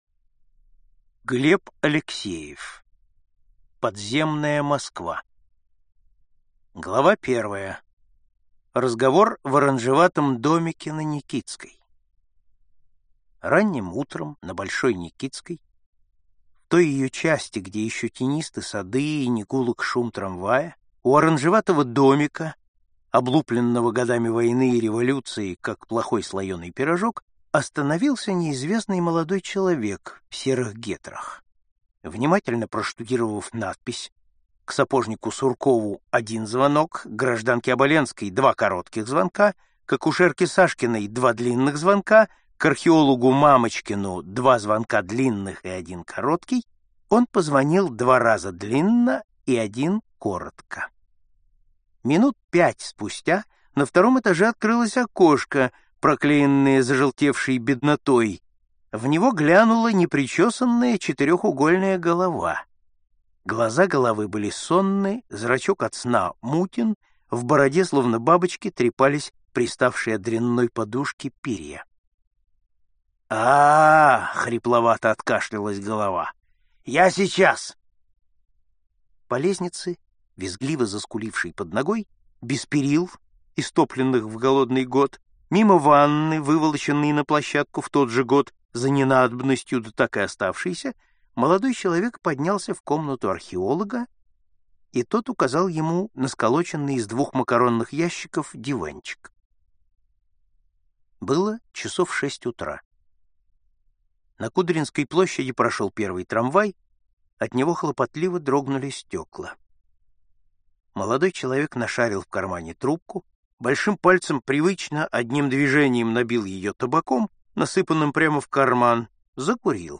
Аудиокнига Подземная Москва | Библиотека аудиокниг